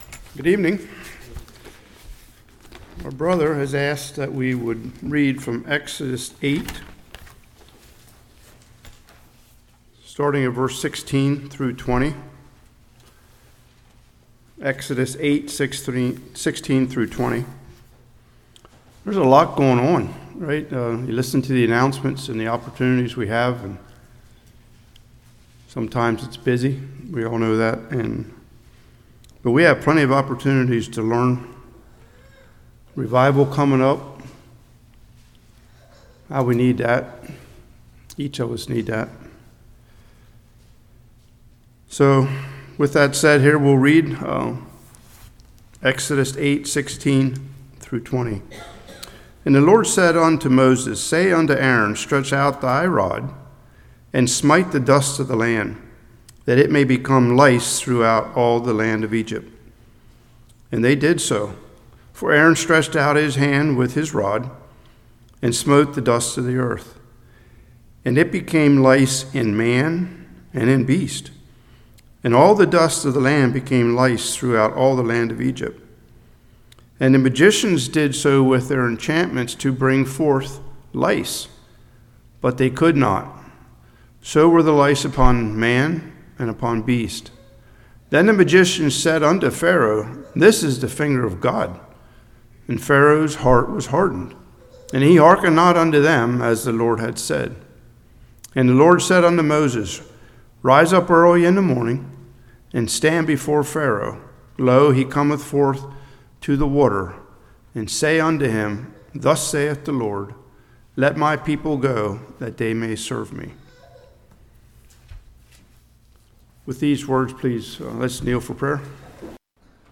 Exodus 8:16-20 Service Type: Evening God is who He says He is